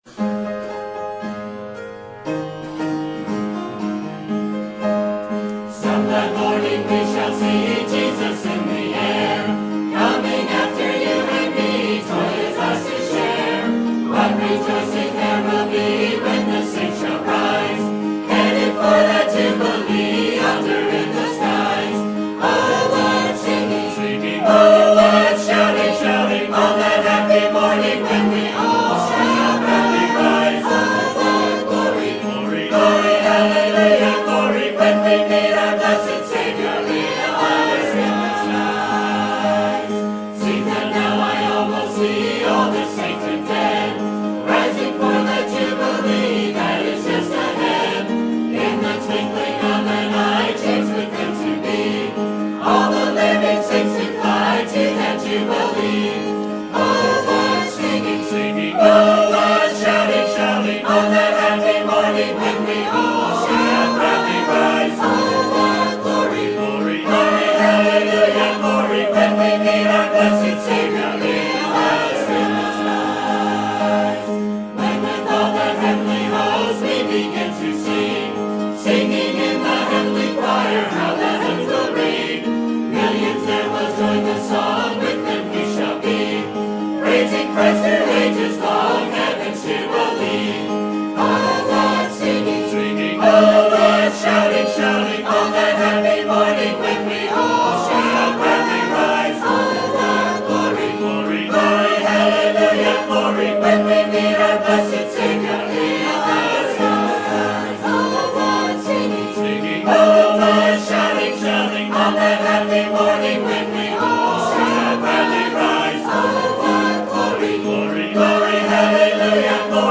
Sermon Topic: Winter Revival 2015 Sermon Type: Special Sermon Audio: Sermon download: Download (19.1 MB) Sermon Tags: Daniel Revival Purposed Heart